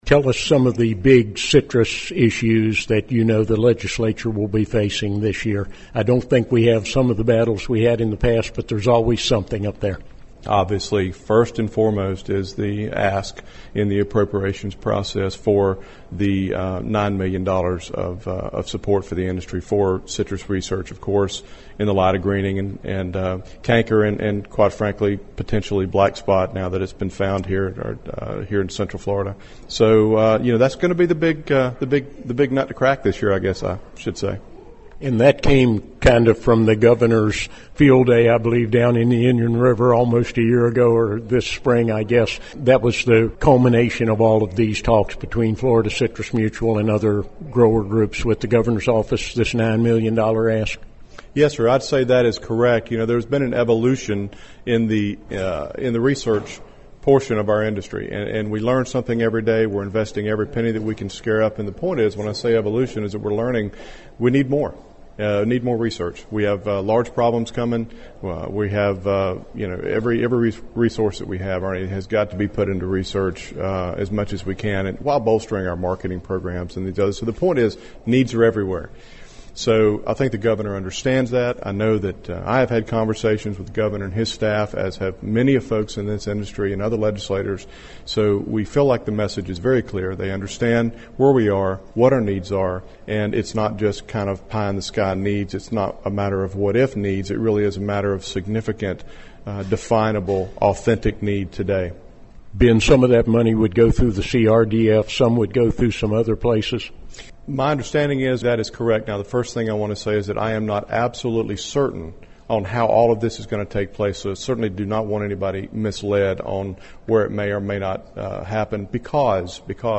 State Rep. Ben Albritton in this report says obtaining millions of dollars for citrus research funding is his big legislative goal this year.